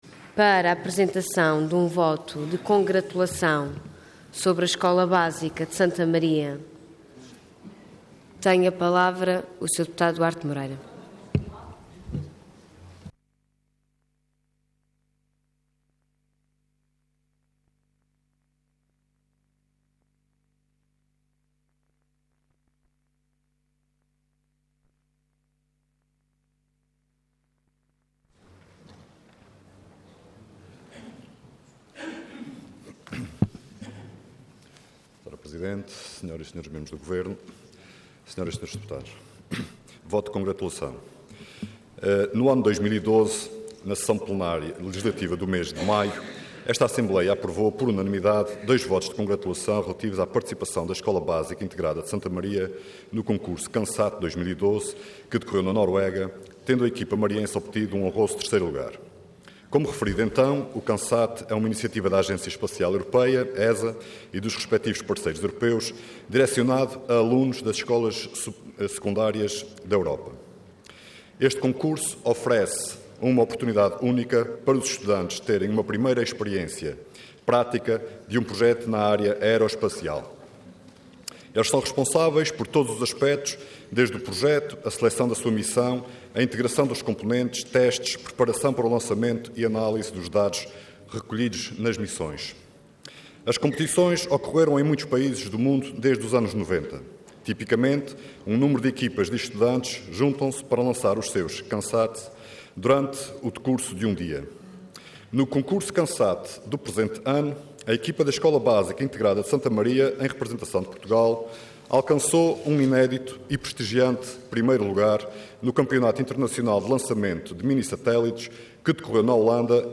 Detalhe de vídeo 16 de abril de 2013 Download áudio Download vídeo Diário da Sessão Processo X Legislatura Escola Básica Integrada de Santa Maria vence concurso CanSat 2013. Intervenção Voto de Congratulação Orador Duarte Moreira Cargo Deputado Entidade PS